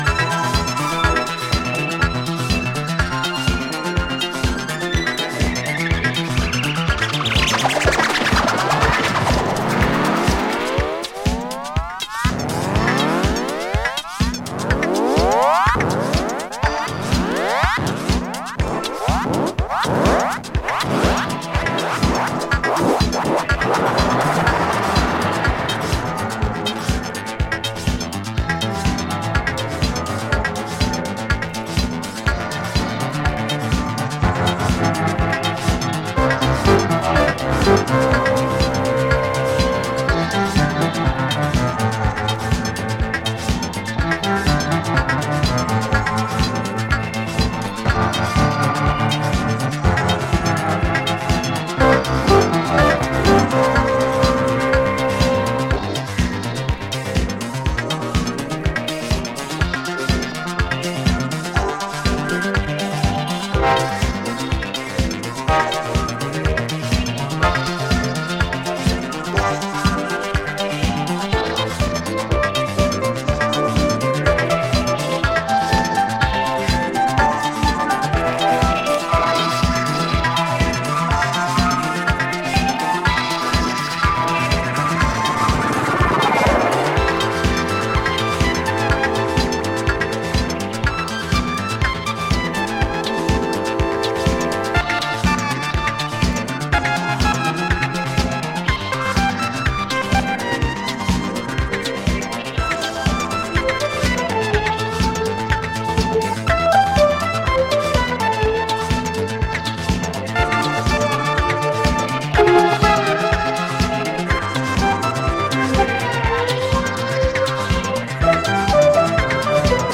fully instrumental